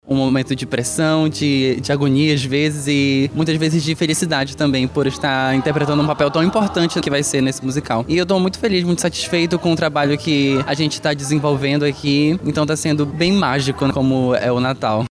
SONORA-1-ESPETACULO-NATAL-TEATRO-2.mp3